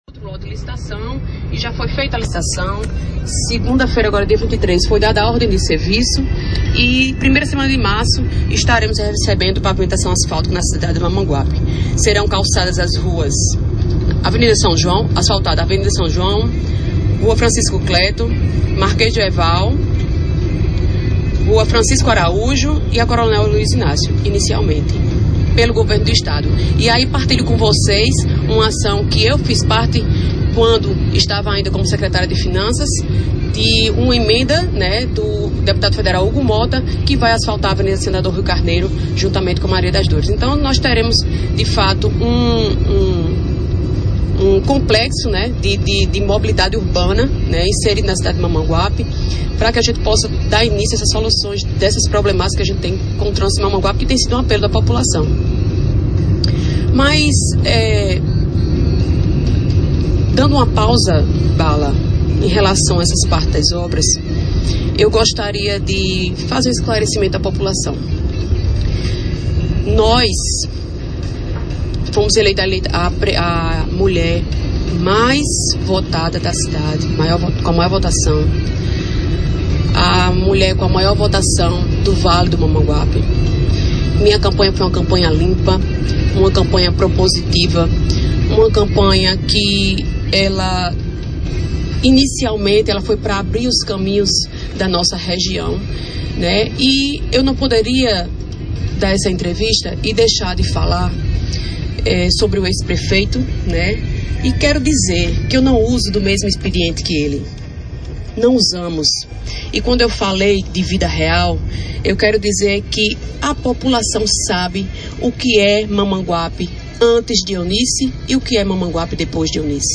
A deputada estadual Danielle do Vale anunciou, durante entrevista à Rádio Correio do Vale fm, na manhã desta segunda-feira (30), novos investimentos em pavimentação asfáltica em diversas ruas em Mamanguape.